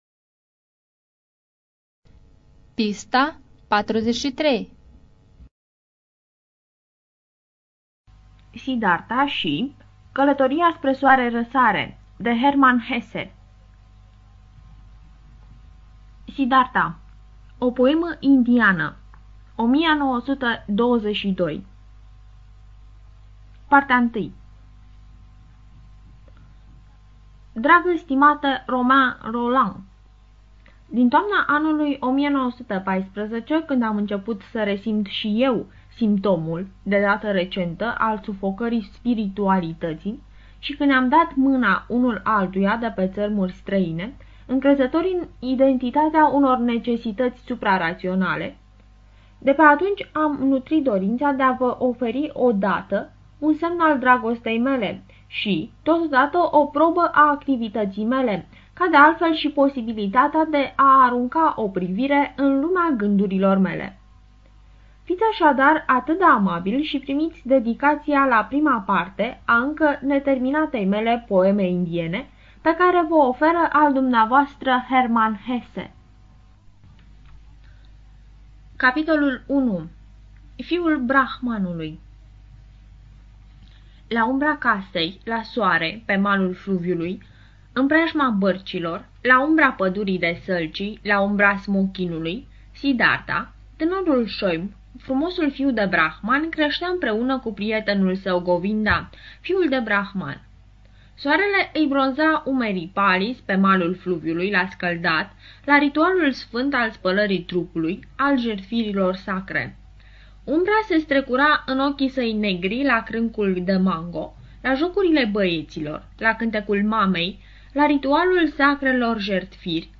Студия звукозаписиНациональный Информационно-реабилитационный Центр Ассоциации Незрячих Молдовы